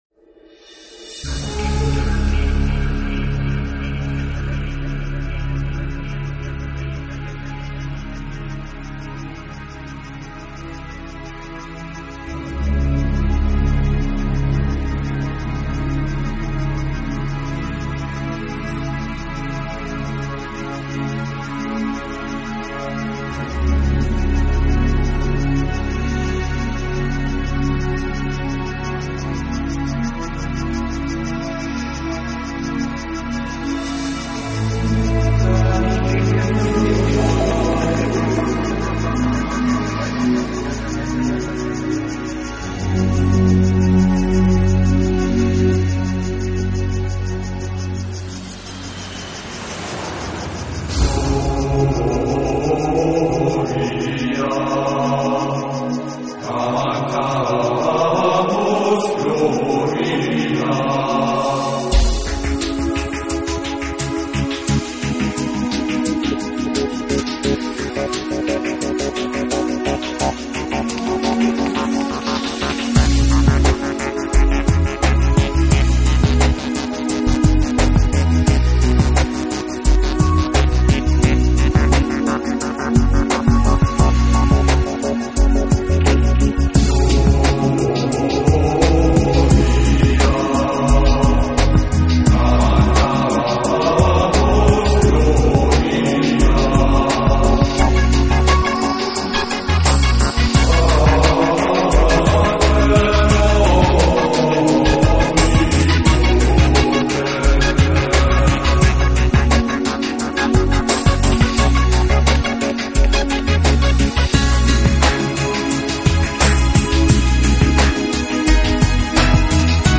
专辑类型：New Age